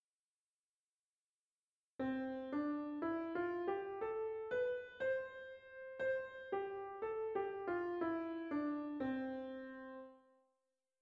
Ejemplo de tresillos en una melodía